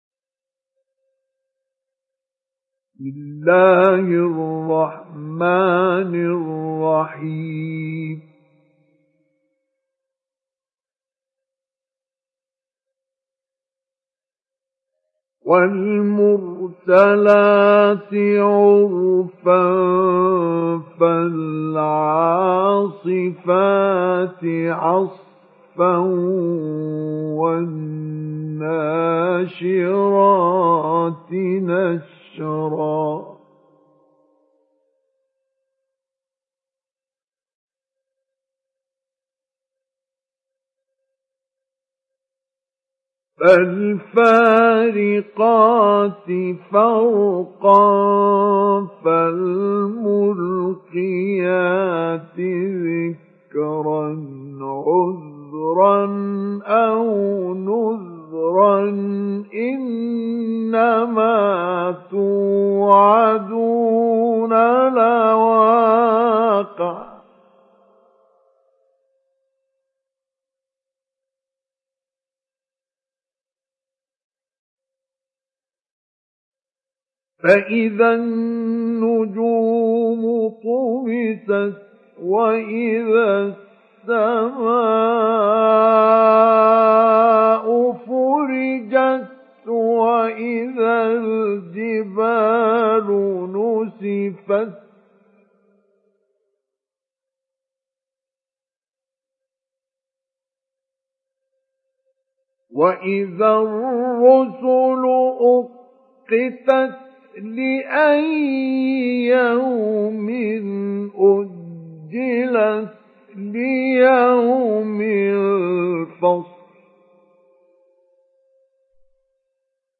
Sourate Al Mursalat Télécharger mp3 Mustafa Ismail Mujawwad Riwayat Hafs an Assim, Téléchargez le Coran et écoutez les liens directs complets mp3
Télécharger Sourate Al Mursalat Mustafa Ismail Mujawwad